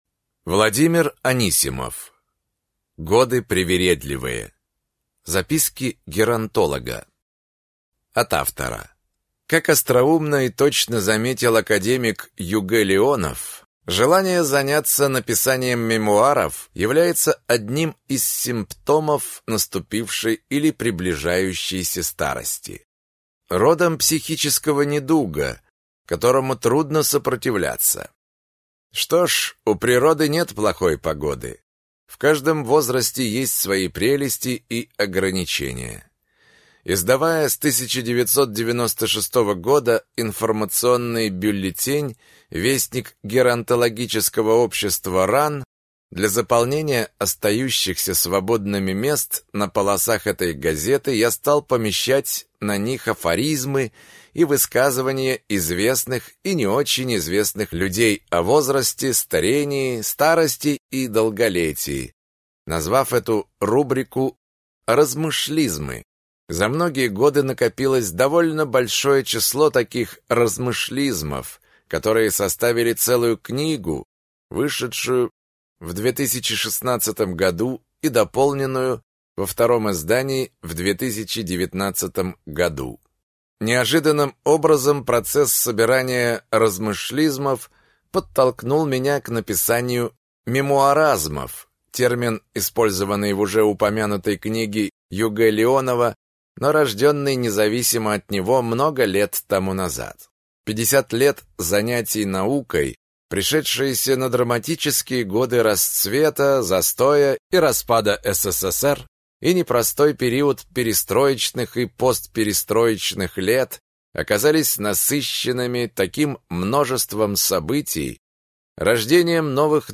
Аудиокнига Годы привередливые. Записки геронтолога | Библиотека аудиокниг